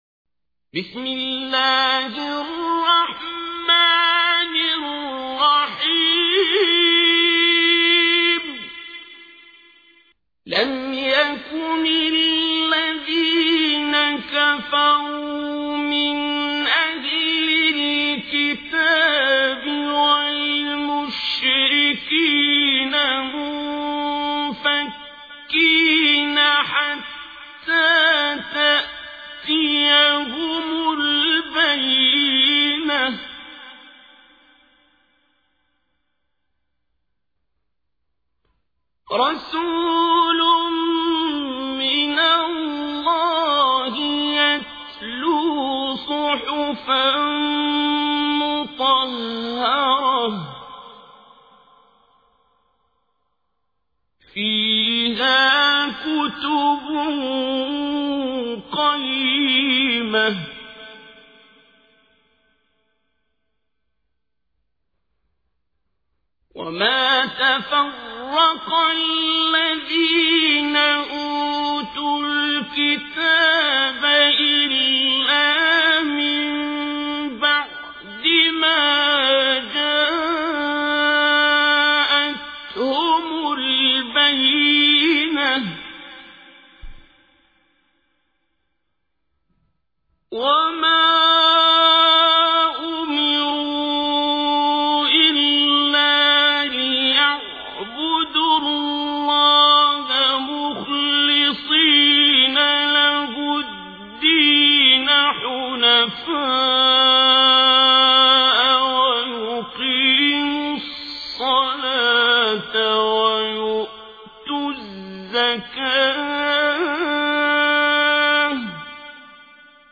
تحميل : 98. سورة البينة / القارئ عبد الباسط عبد الصمد / القرآن الكريم / موقع يا حسين